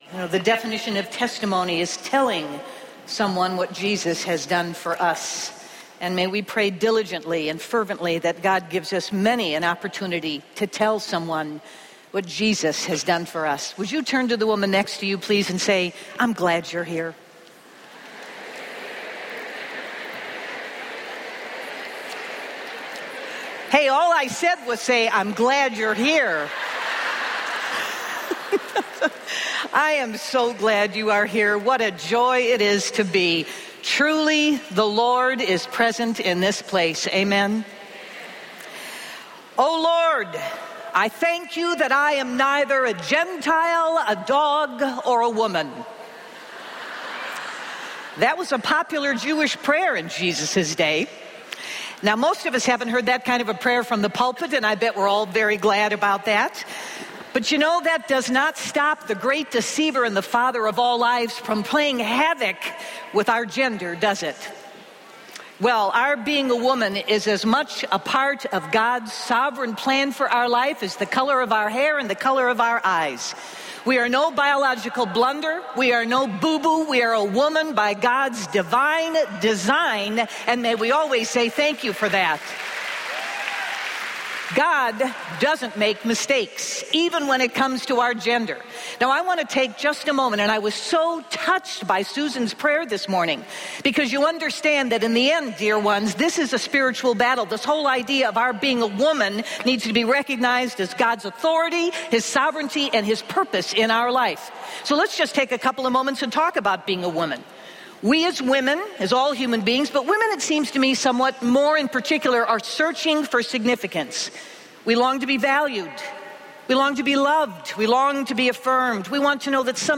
The Woman at the Well | True Woman '12 | Events | Revive Our Hearts